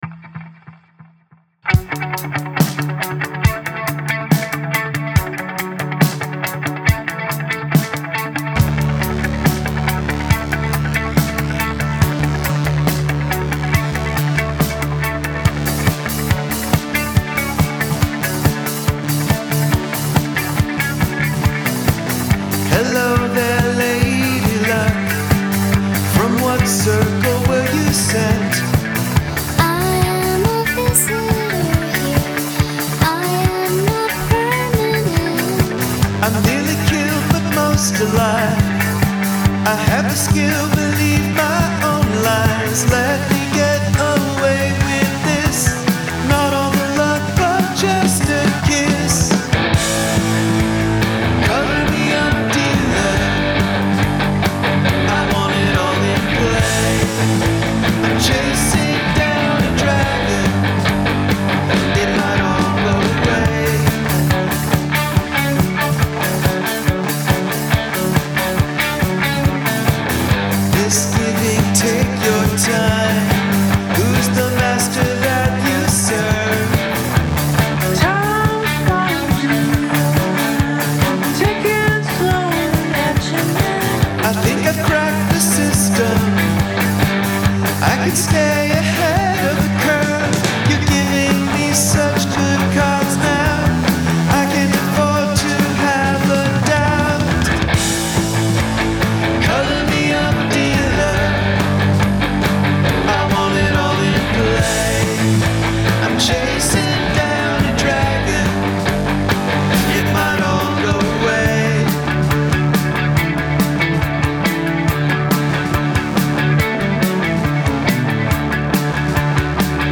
Sampled Vocal Hook